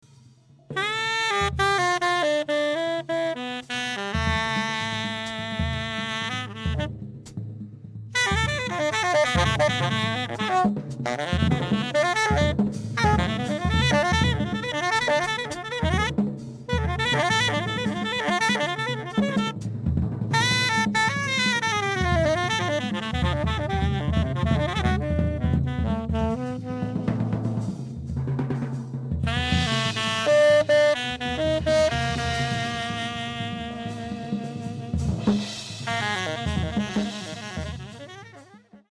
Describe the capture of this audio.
Recorded and re-mixed at Red Gate Studio, Kent, New York